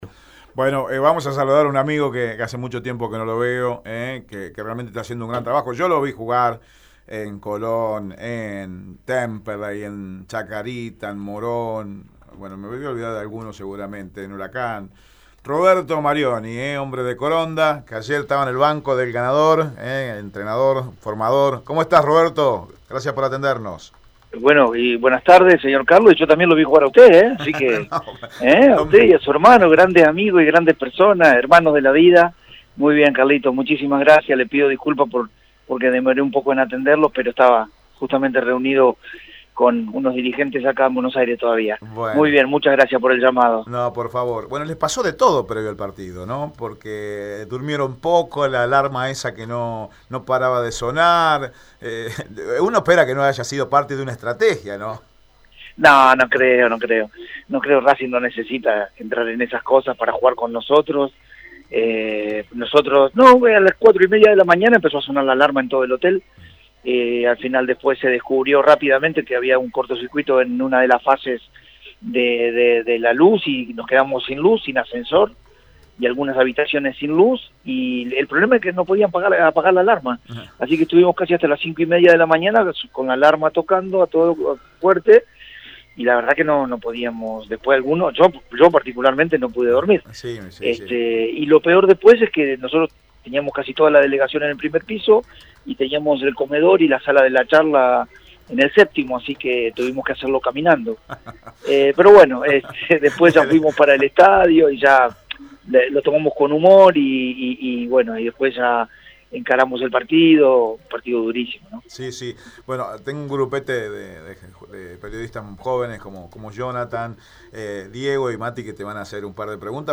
En dialogo con Radio EME